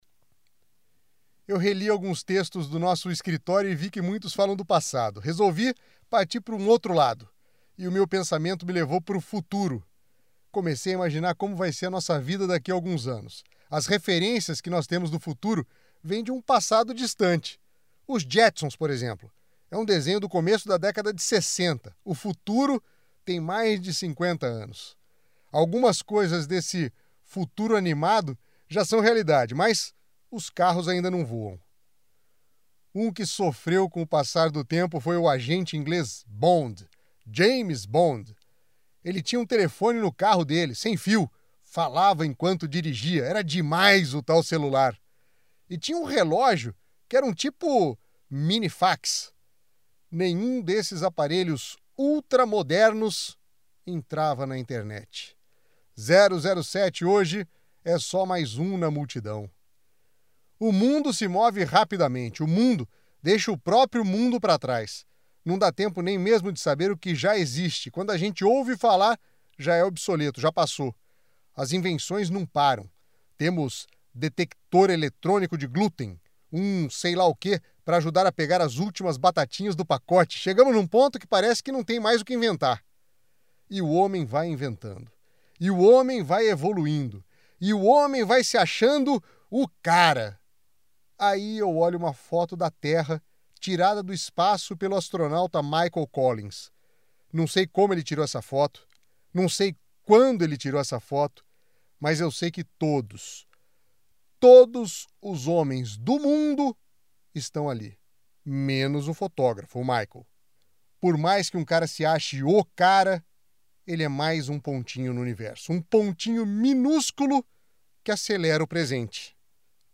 Agora os textos do “Escritório de Hoje” são, além de escritos, narrados.